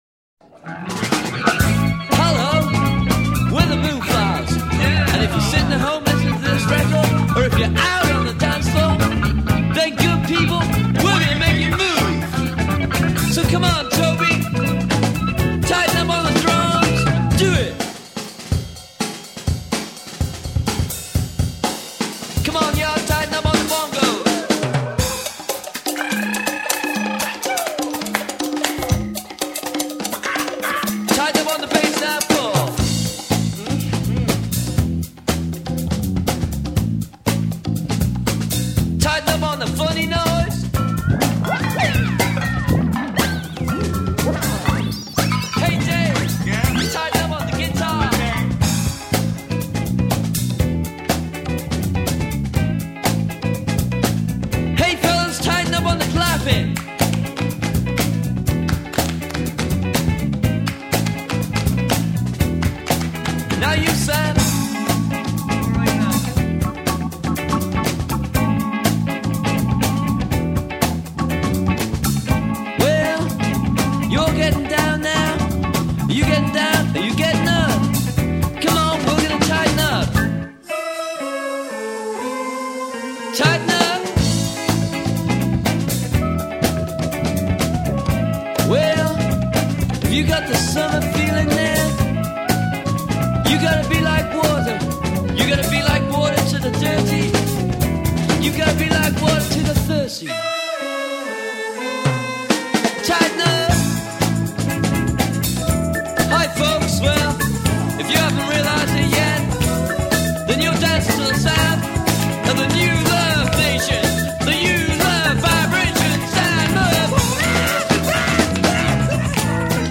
our cover